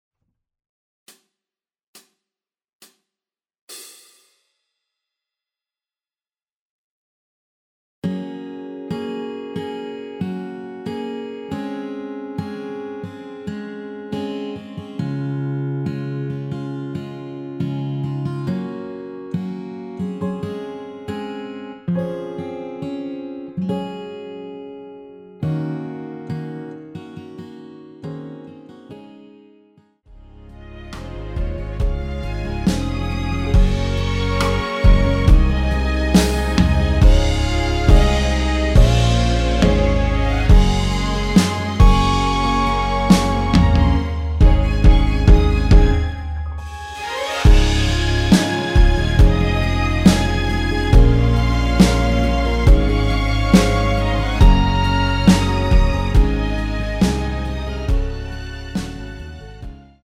전주 없이 시작하는 곡이라서 시작 카운트 만들어놓았습니다.(미리듣기 확인)
원키에서(+5)올린 MR입니다.
Db
앞부분30초, 뒷부분30초씩 편집해서 올려 드리고 있습니다.